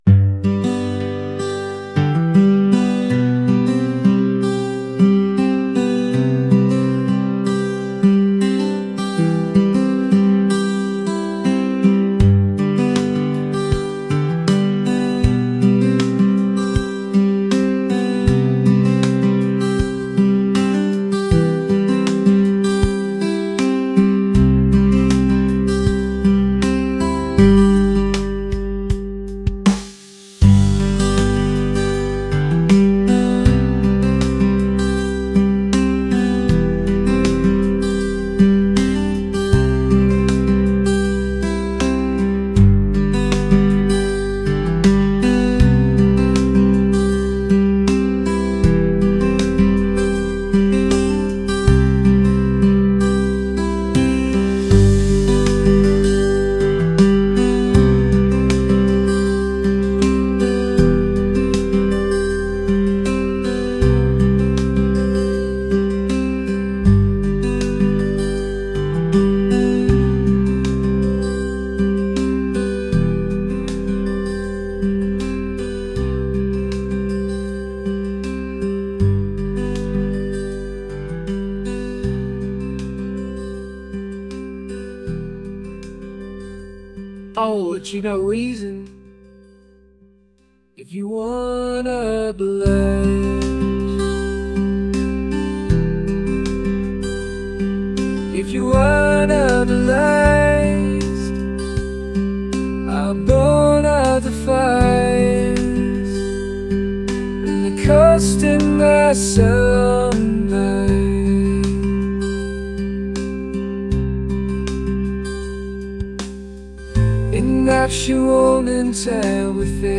folk | acoustic